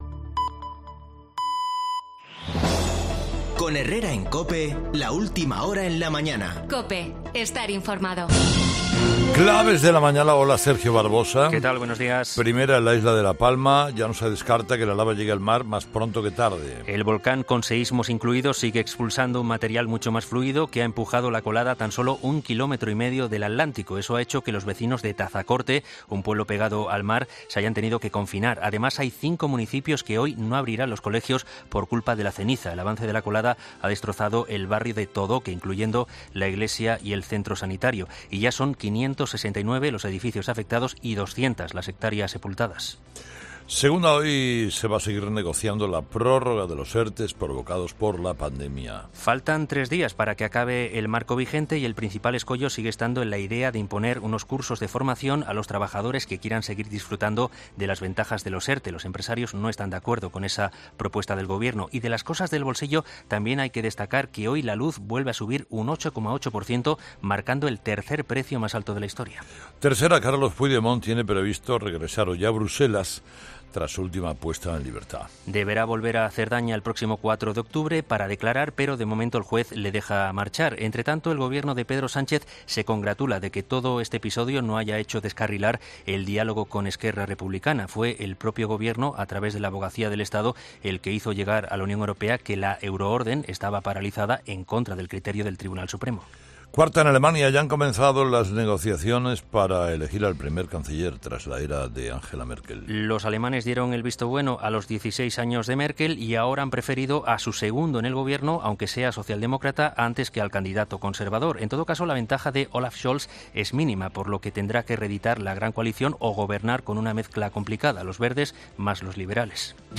El presidente del Foro La Toja, Josep Piqué, ha analizado en COPE el posible desenlace de las elecciones alemanas y otras cuestiones de actualidad